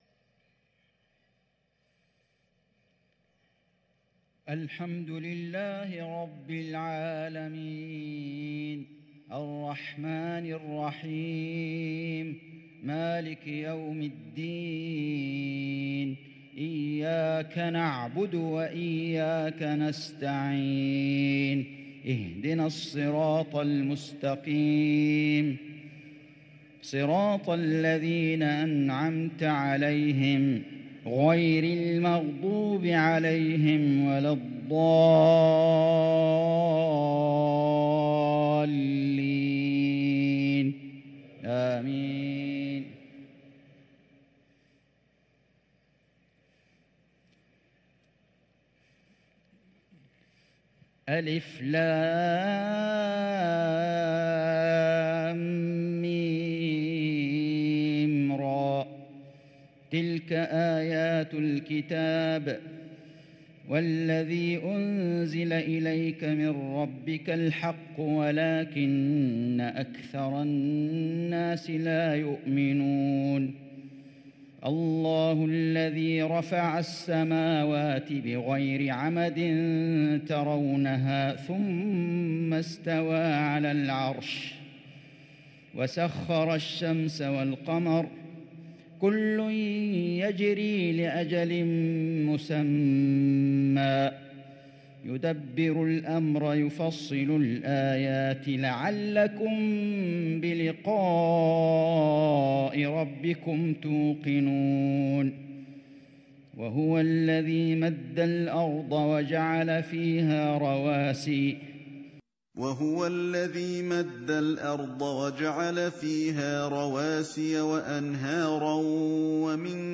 صلاة المغرب للقارئ فيصل غزاوي 16 ربيع الأول 1444 هـ
تِلَاوَات الْحَرَمَيْن .